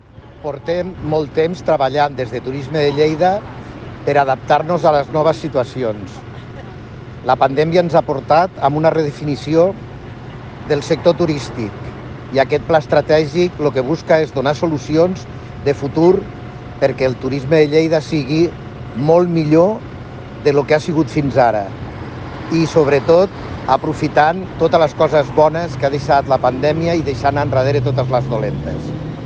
Compartir Facebook Twitter Whatsapp Descarregar ODT Imprimir Tornar a notícies Fitxers relacionats Tall de veu de Paco Cerdà sobre el pla estratègic de Turisme de Lleida (66.7 KB) T'ha estat útil aquesta pàgina?
tall-de-veu-de-paco-cerda-sobre-el-pla-estrategic-de-turisme-de-lleida